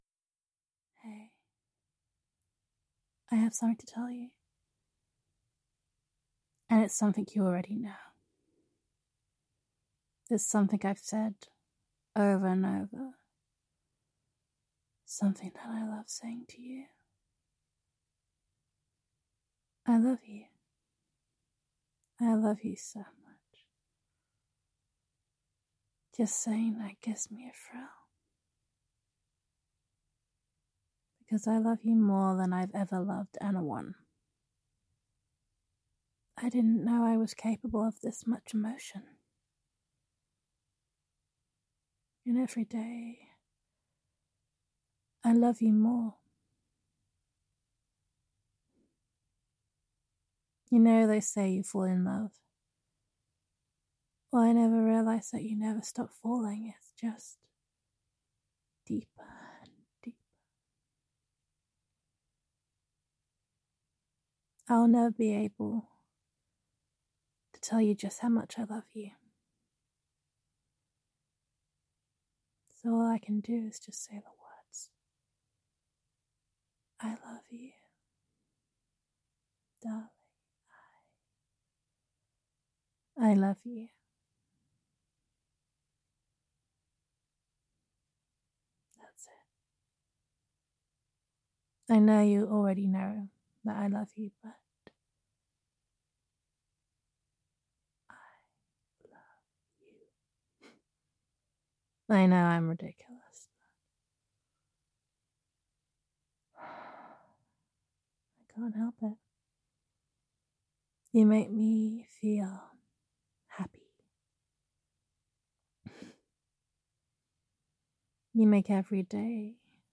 [F4A] Falling [I Love Saying the Words][Gender Neutral][Short & Sweet][Girlfriend Voicemail]